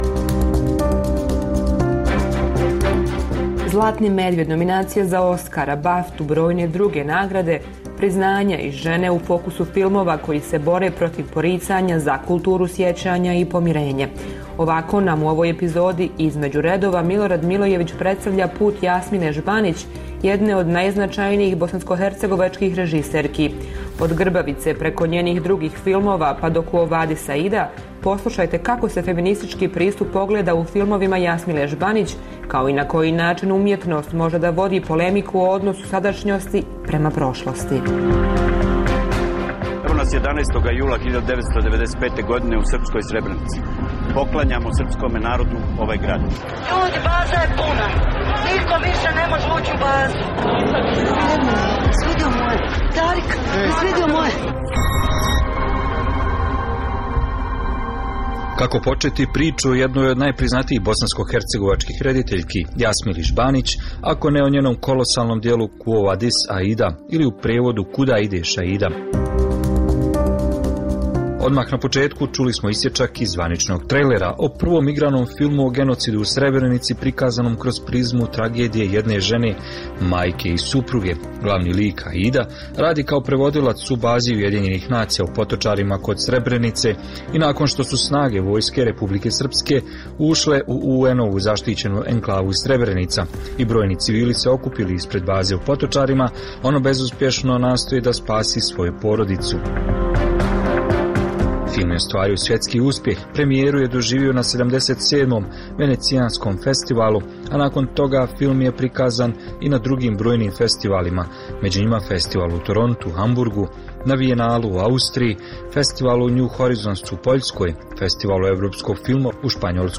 Emisija namijenjena slušaocima u Crnoj Gori. Sadrži lokalne, regionalne i vijesti iz svijeta, rezime sedmice, tematske priloge o aktuelnim dešavanjima u Crnoj Gori i temu iz regiona.